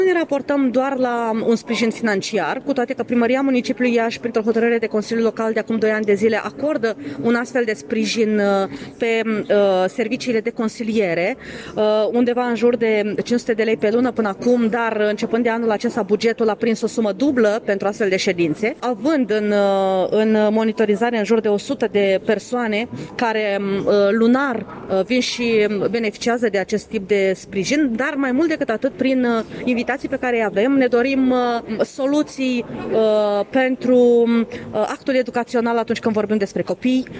Legislația privind persoanele și copiii cu autism din România necesită completări, conform concluziilor dezbaterii organizate astăzi la Iași, de Ziua Internațională de Conștientizare a Autismului.